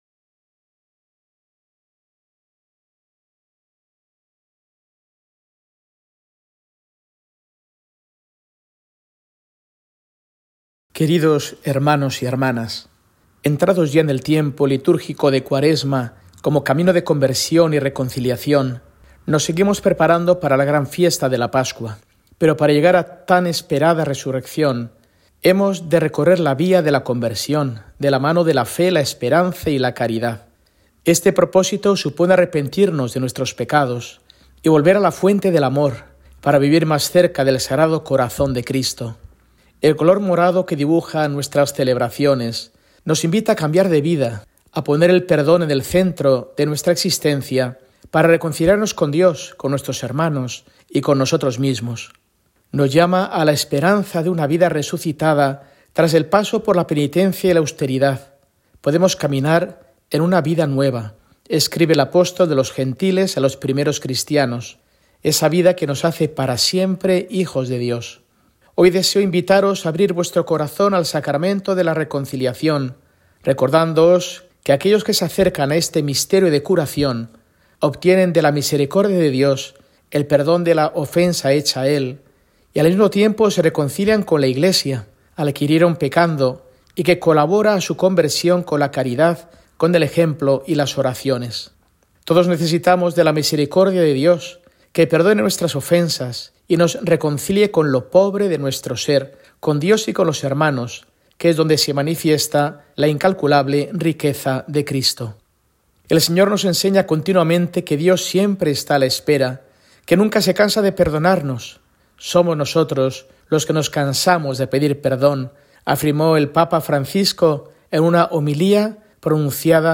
Mensaje semanal de Mons. Mario Iceta Gavicagogeascoa, arzobispo de Burgos, para el domingo, 30 de marzo de 2025, IV del Tiempo de Cuaresma